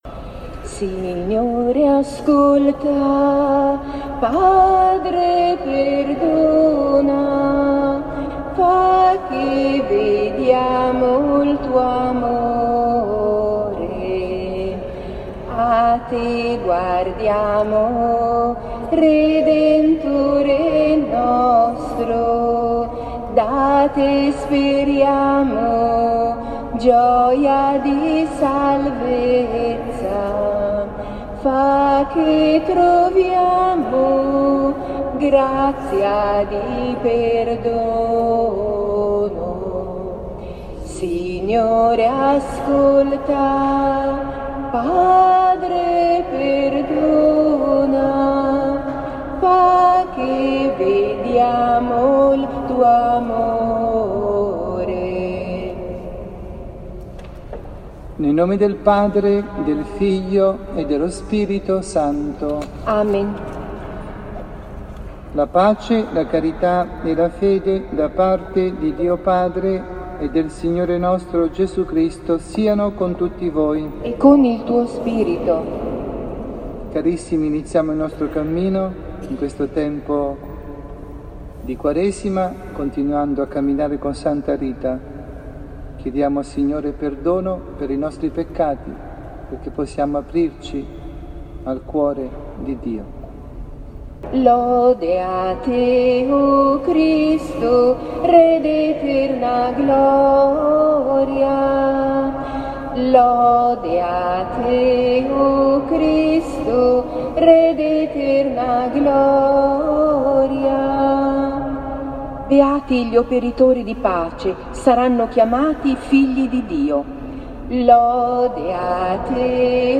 Omelia
dalla Parrocchia S. Rita – Milano